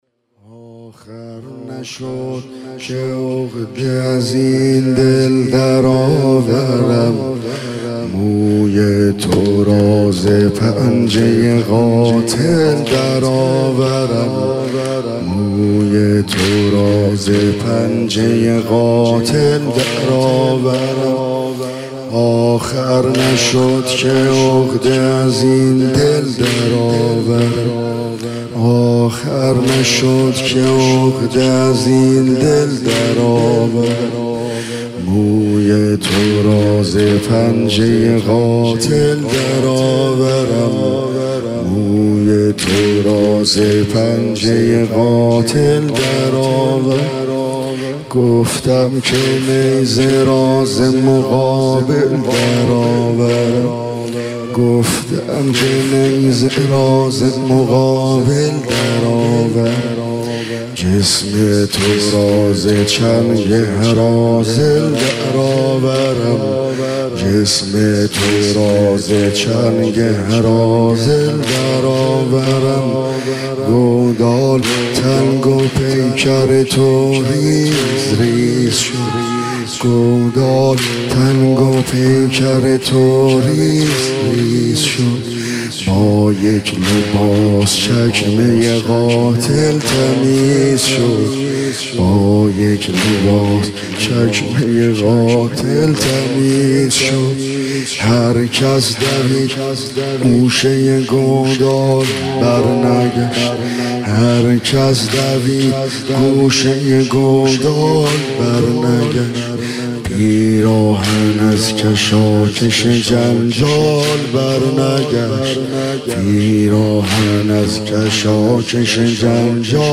مداحی جدید حاج عبدالرضا هلالی شب دهم محرم۹۸ هیات الرضا دوشنبه 18 شهریور ۱۳۹۸
مداحی دهه محرم ۹۸ عبدالرضا هلالی